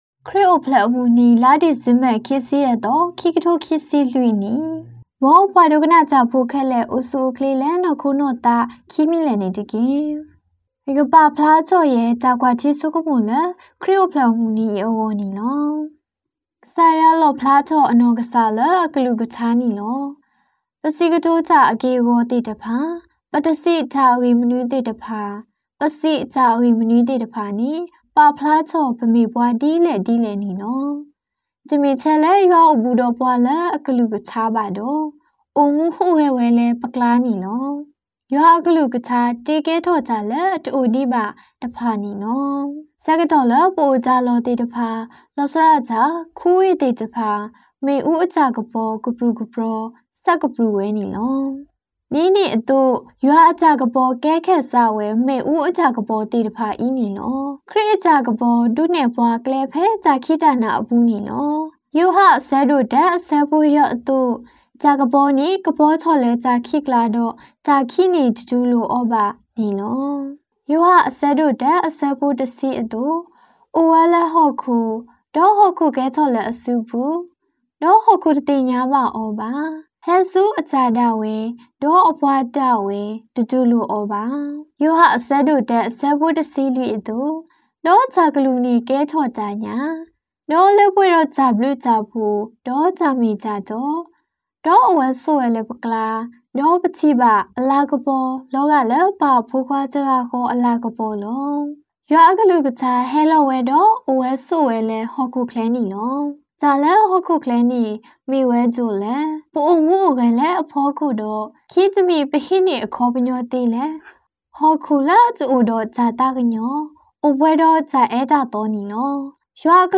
Christmas-Homily-audio.mp3